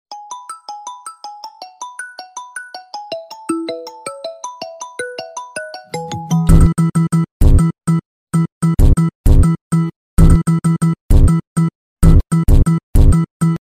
Рингтоны ремиксы